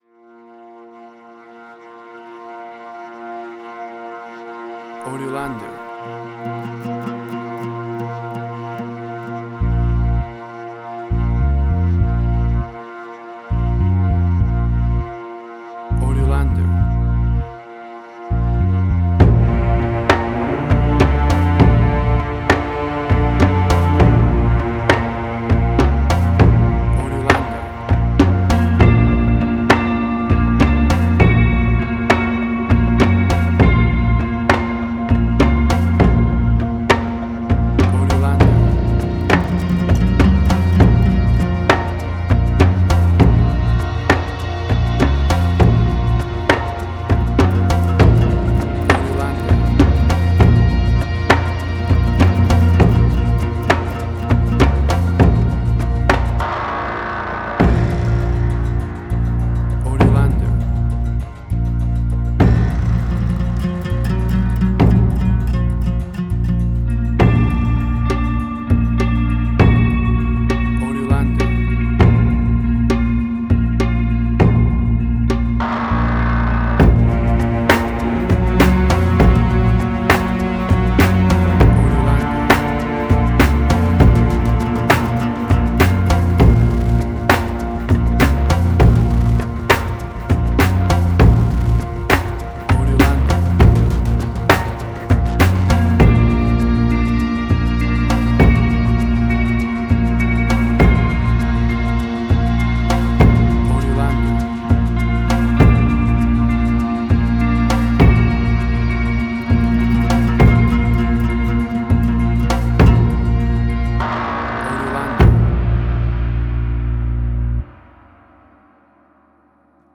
Middle Eastern Fusion.
Tempo (BPM): 100